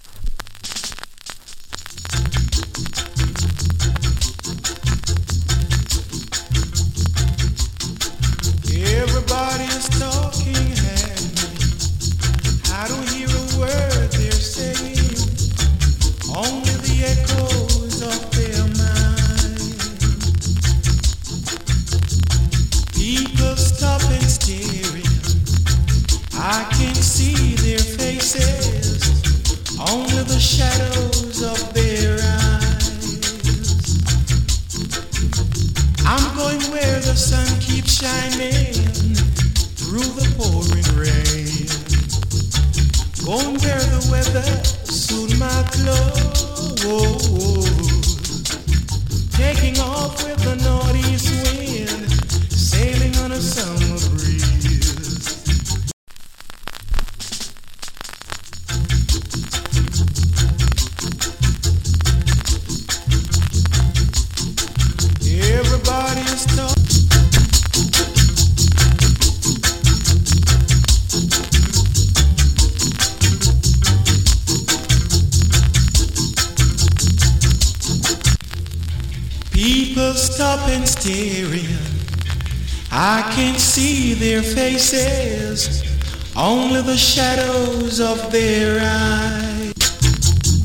チリ、パチノイズ有り。